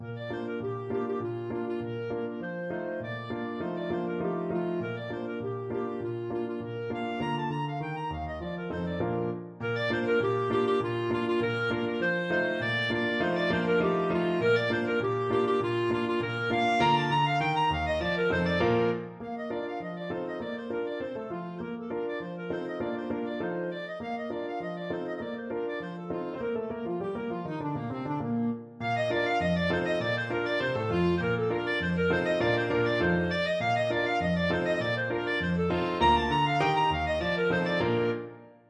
Allegretto
2/4 (View more 2/4 Music)
Bb4-Bb6